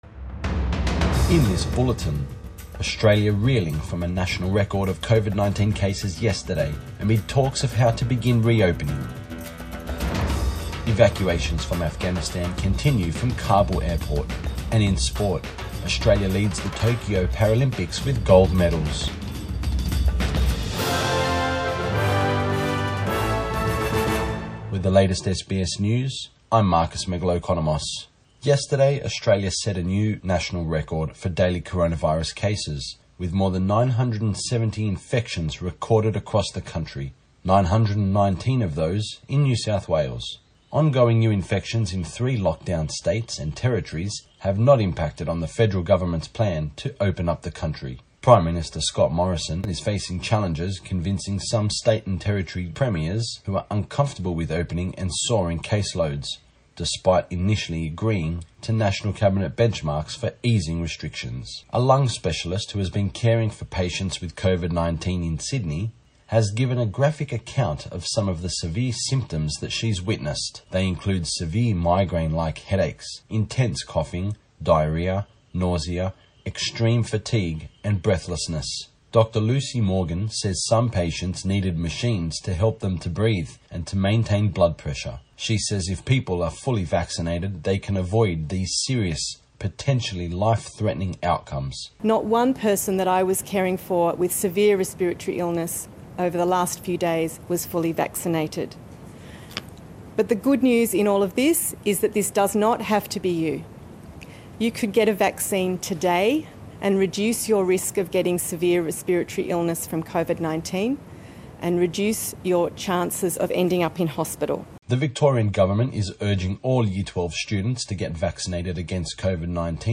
AM bulletin 26 August 2021